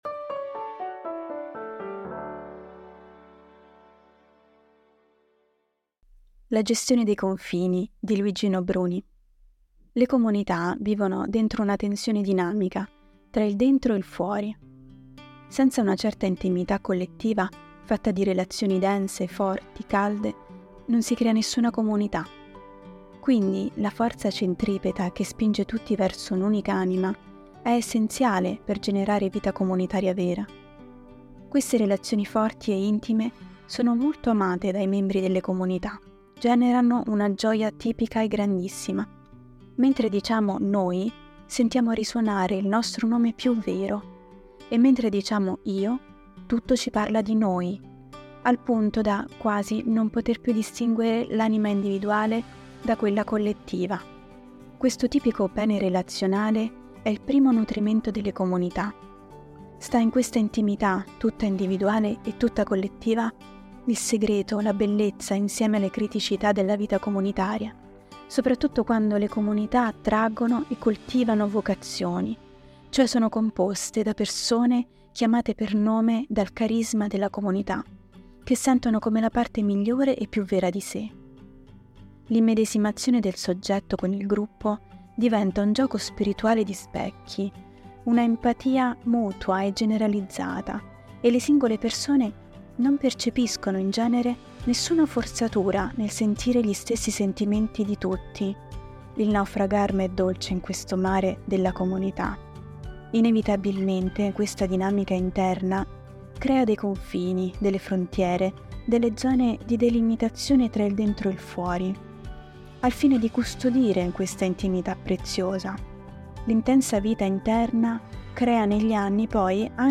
Per ogni numero, ci sarà una selezione di articoli letti dai nostri autori e collaboratori.
Al microfono, i nostri redattori e i nostri collaboratori.